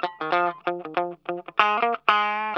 LO-FI 3R.wav